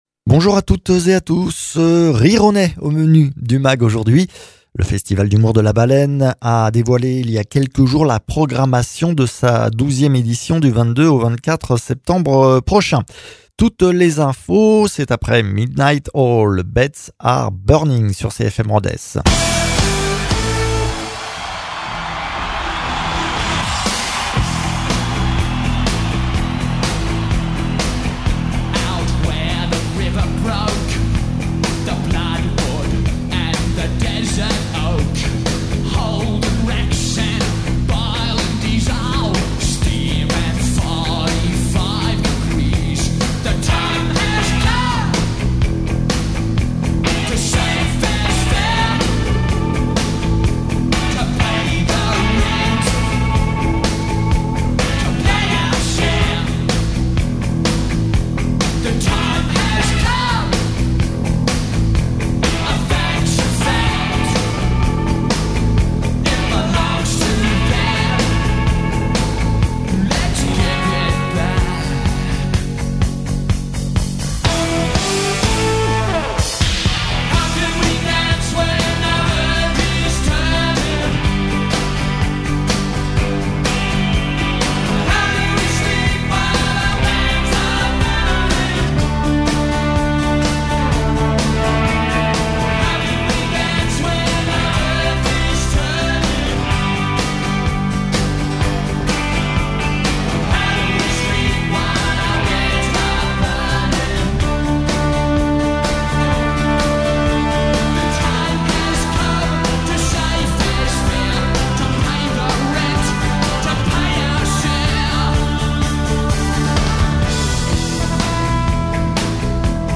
Gulistan Dincel, adjointe à la culture à la mairie d’Onet le Chateau
Valérie Abadie-Roques, élue municipales et départementale